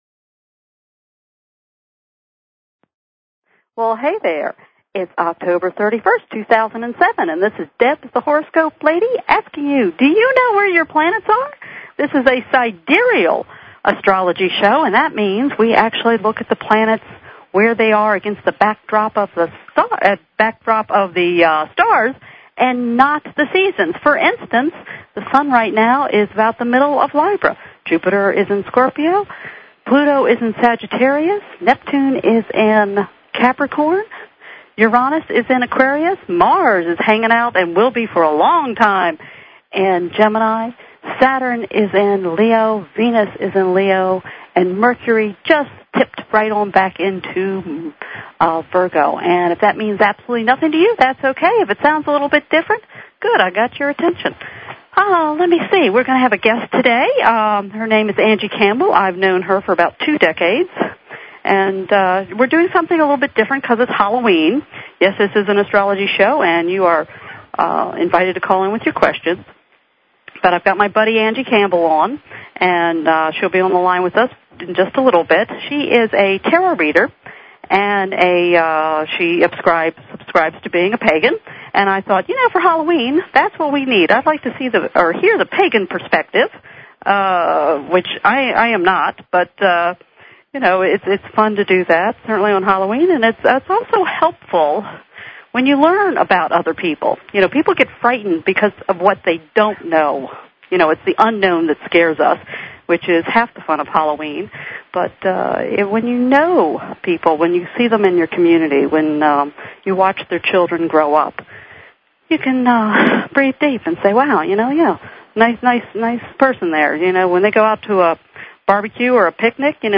Her guests include other leading sidereal astrologers and the occasional celebrity who has found sidereal astrology useful.
Talk Show